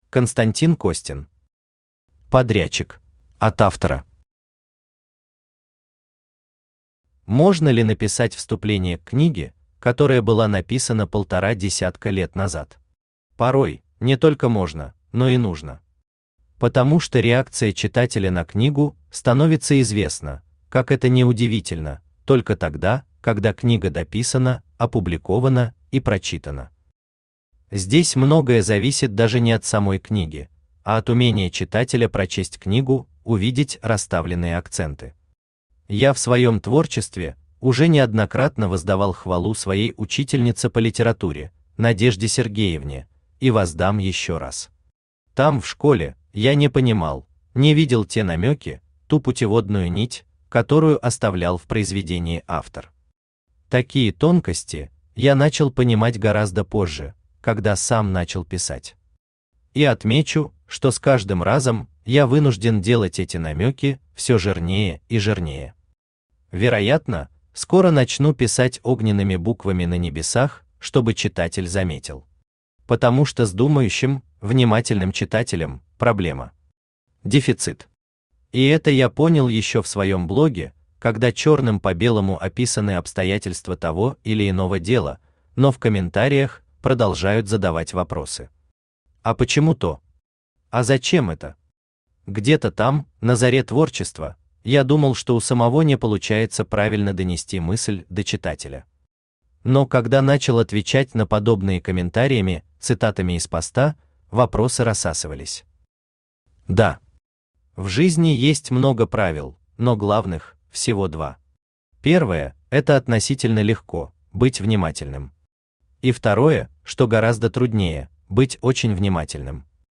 Аудиокнига Подрядчик | Библиотека аудиокниг
Aудиокнига Подрядчик Автор Константин Александрович Костин Читает аудиокнигу Авточтец ЛитРес.